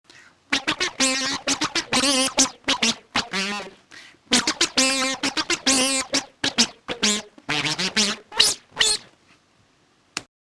Oomingmak turns your guitar into an analogue monosynth.
The sounds below are examples of what Oomingmak can do.
A whimsical little piece